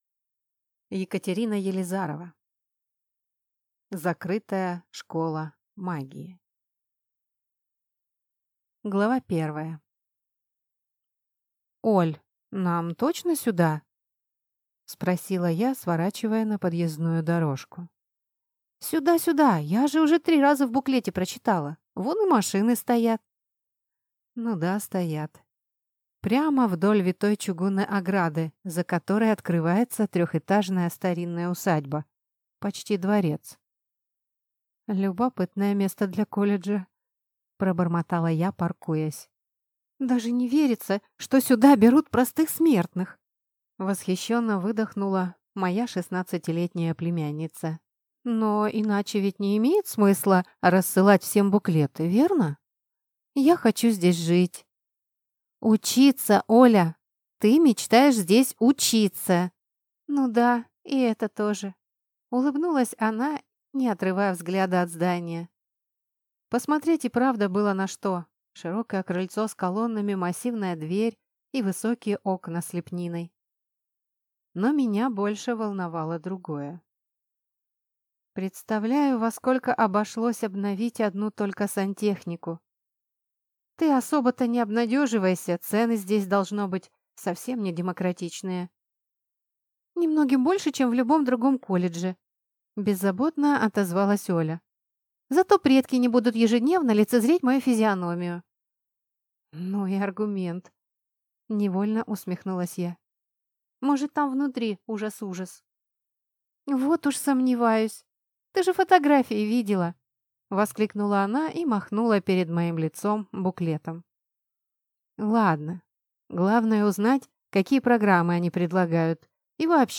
Аудиокнига Закрытая школа магии | Библиотека аудиокниг